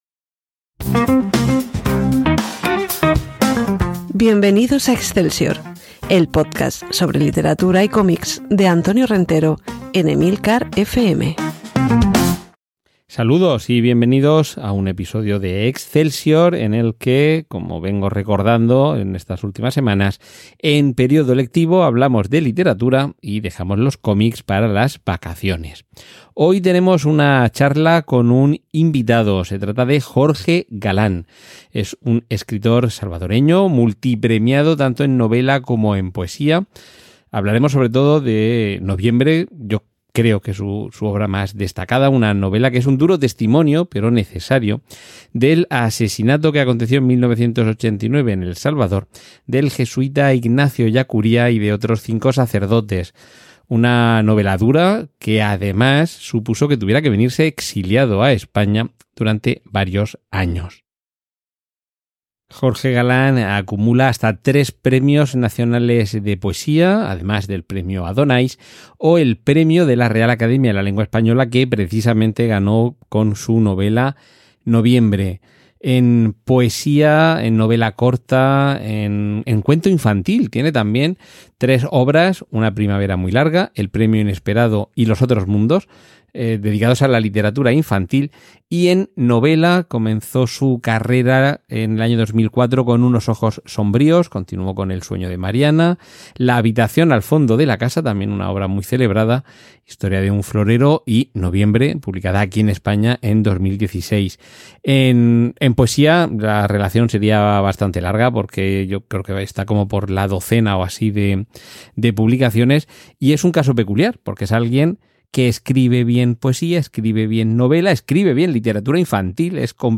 Esta semana conversamos con Jorge Galán, escritor salvadoreño multipremiado en novela y poesía, autor de “Noviembre”, testimonio duro pero necesario del asesinato en 1989 en El Salvador del jesuita Ignacio Ellacuría y otros 5 sacerdotes.